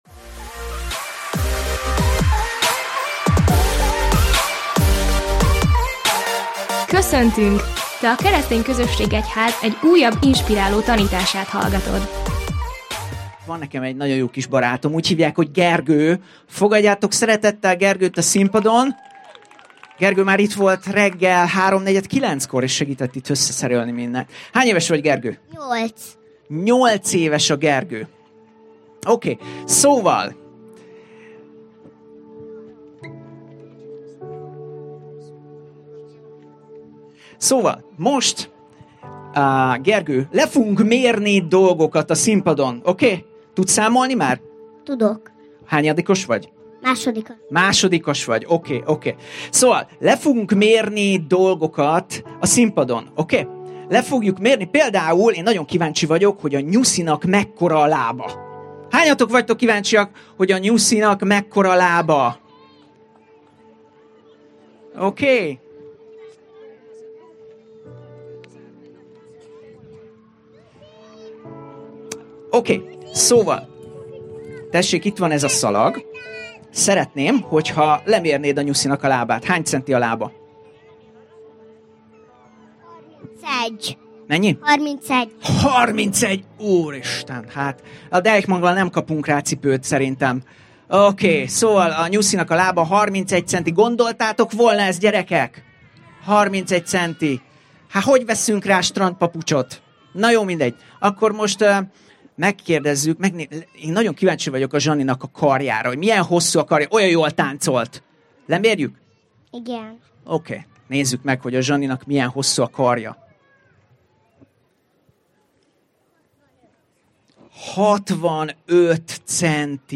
Szenvedélyes, erőteljes üzeneteivel minden héten inspirál mindannyiunkat, hogy a következő szintre lépjünk.